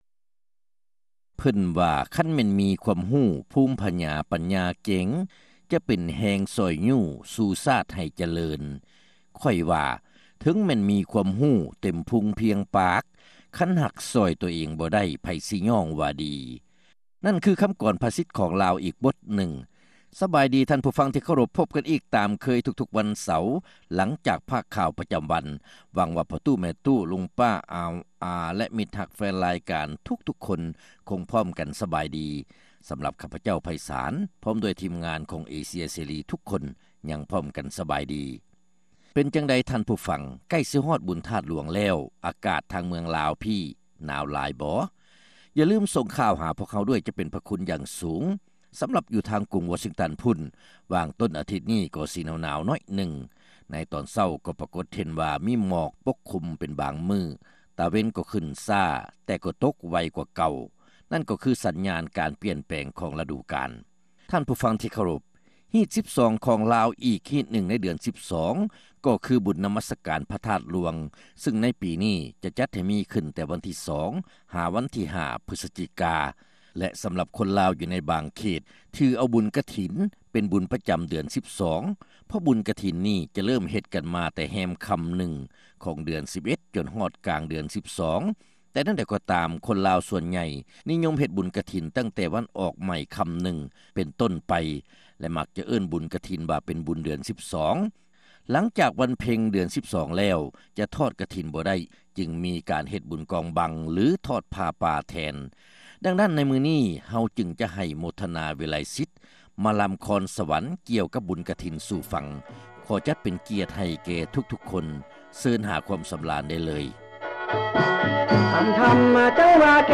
ຣາຍການໜໍລຳ ປະຈຳສັປະດາ ວັນທີ 20 ເດືອນ ຕຸລາ ປີ 2006